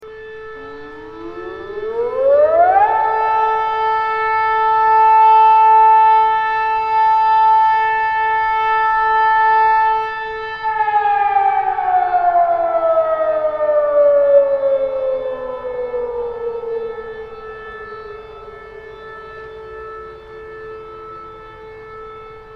دانلود آهنگ هشدار 27 از افکت صوتی اشیاء
دانلود صدای هشدار 27 از ساعد نیوز با لینک مستقیم و کیفیت بالا
جلوه های صوتی